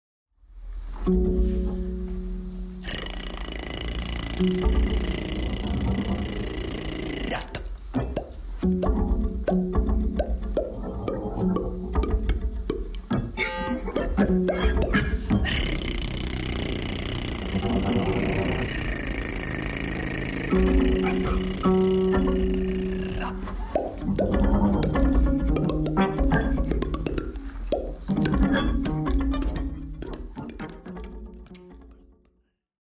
at morphine raum, berlin